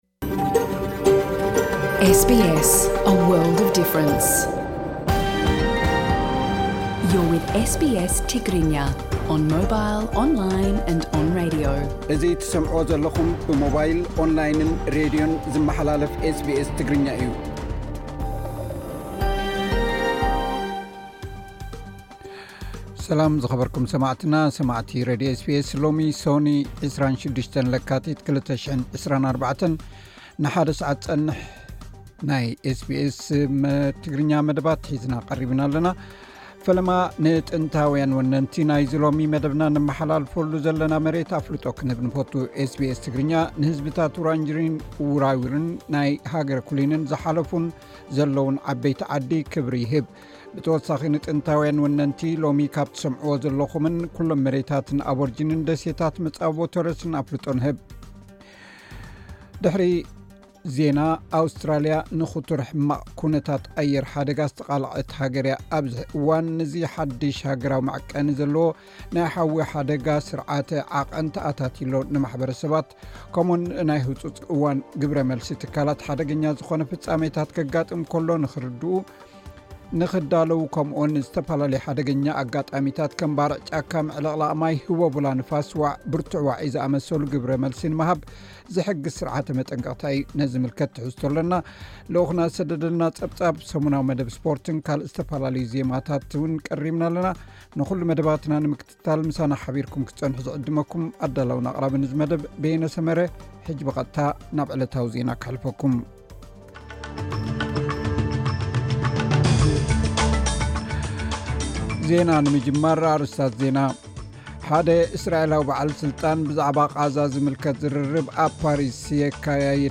ልኡኽና ዝሰደደልና ጸብጻብ፡ ሰሙናዊ መደብ ስፖርትን ካልእ ዝተፈላለዩ ዜማታትን ቀሪብና ኣለና ።